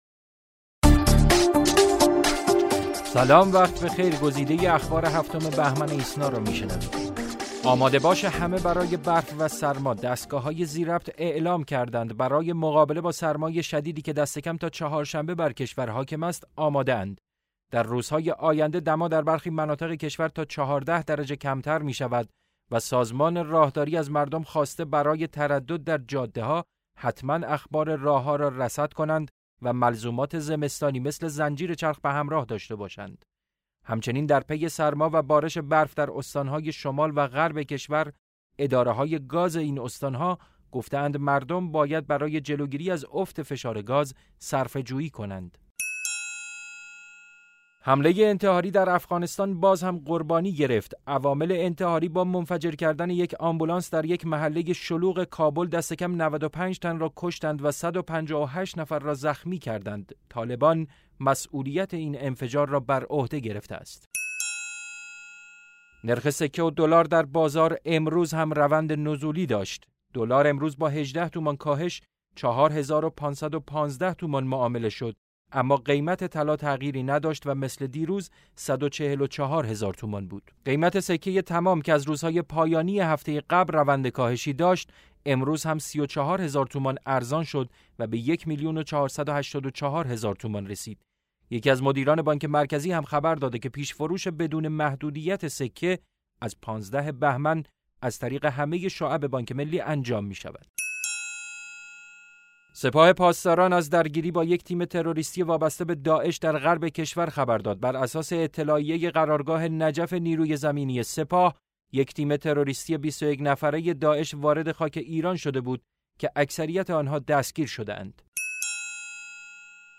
صوت / بسته خبری ۷ بهمن ۹۶